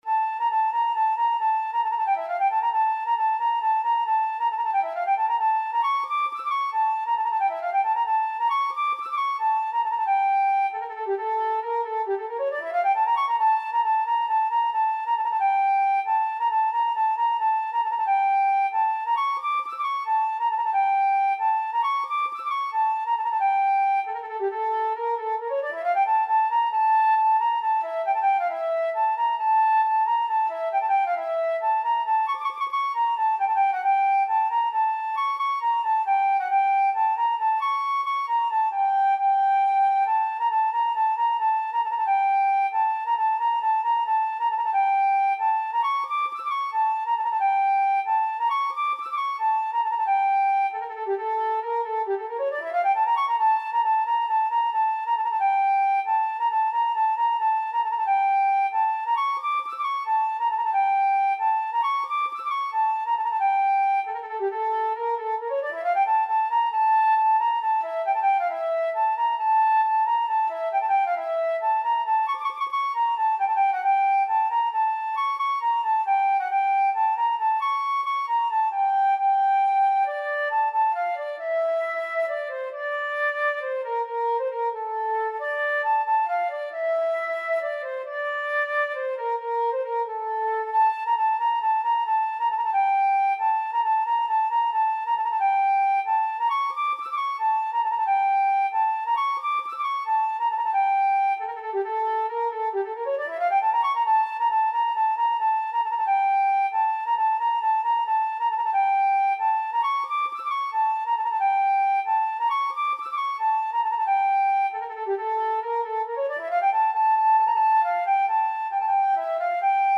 تنظیم شده برای فلوت